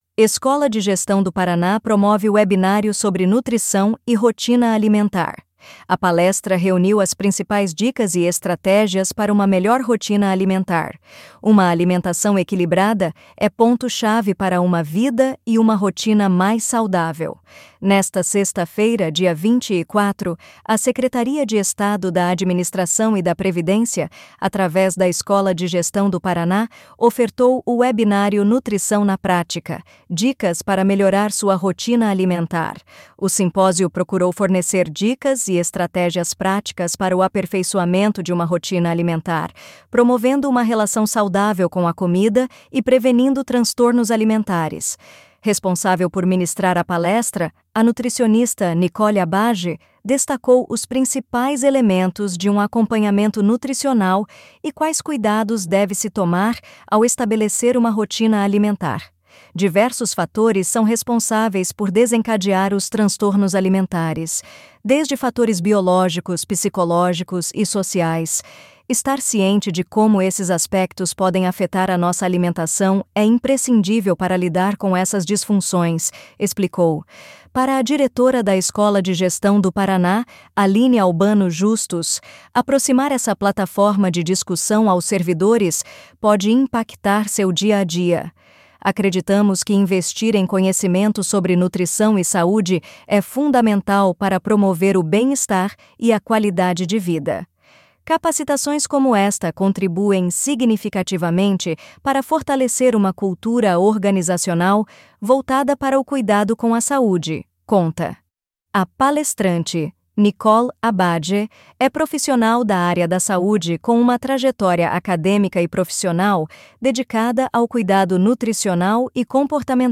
audionoticia_nutricao_mes_05.mp3